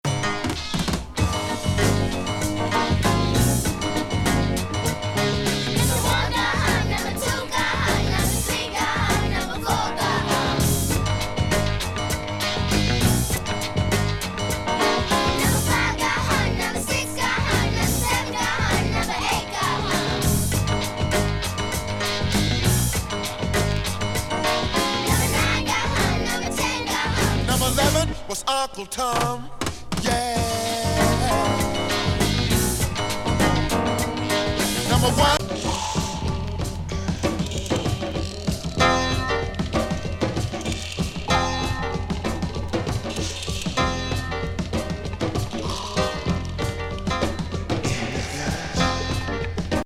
子供声入り